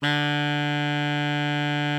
Added more instrument wavs
bari_sax_050.wav